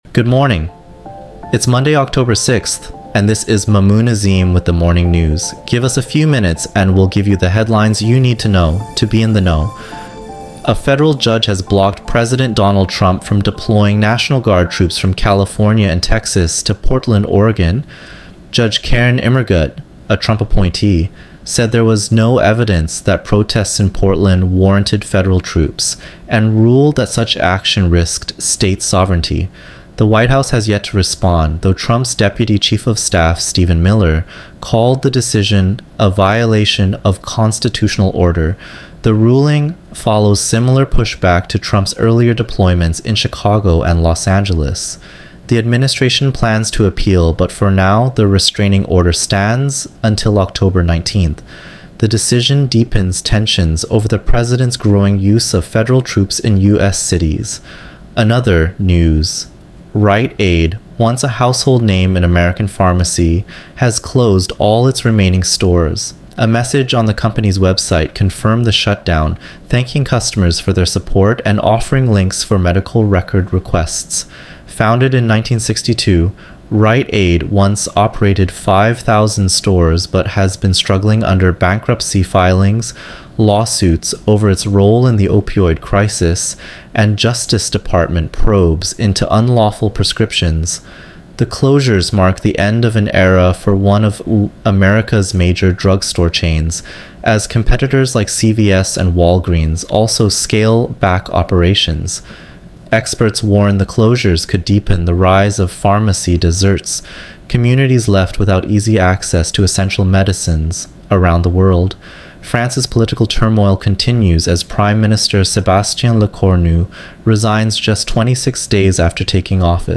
1 The latest Spanish news headlines in English